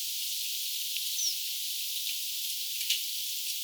vähän erilainen pajusirkkulinnun ääni
vahan_erilainen_pajusirkkulinnun_aani.mp3